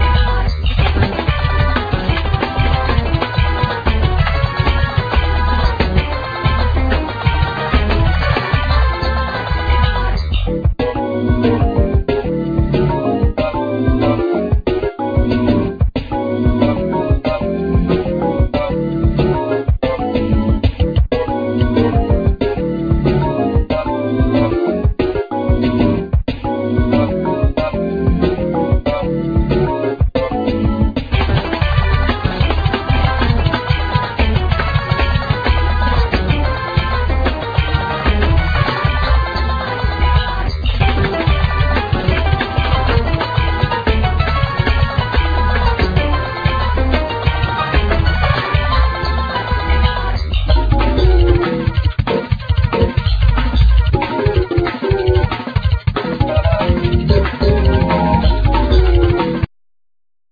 Vocal,Synthsizer,Harmonica
Drums,Keyboards
Guitar,Mandlin
Bass,Melodica
Cello,Bass